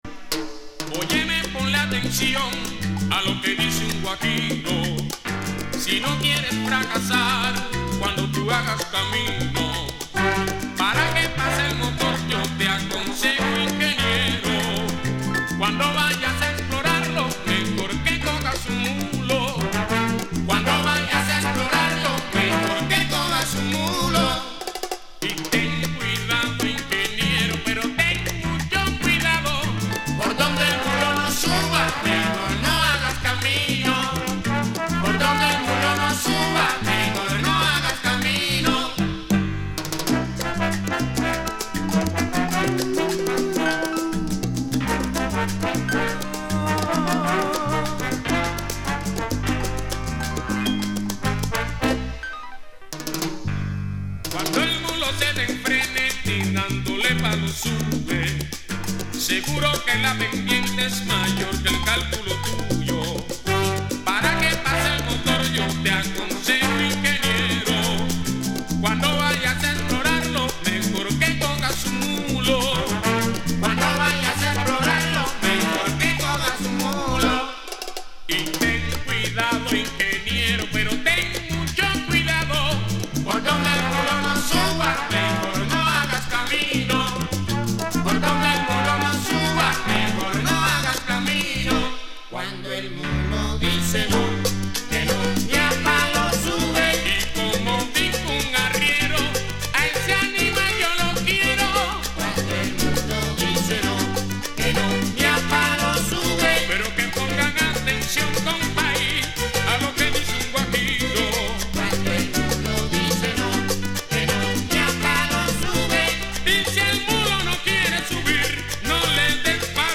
チャングイという リズムで一世風靡した。